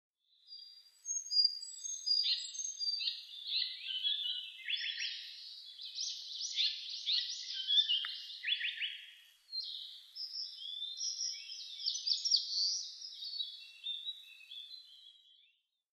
サンコウチョウ　Terpsiphone atrocaudataカササギヒタキ科
日光市稲荷川中流　alt=770m  HiFi --------------
Windows Media Audio FILE MPEG Audio Layer3 FILE  Rec.: SONY MZ-NH1
Mic.: Sound Professionals SP-TFB-2  Binaural Souce
他の自然音：　 エゾムシクイ・センダイムシクイ・キビタキ・ジュウイチ・シジュウカラ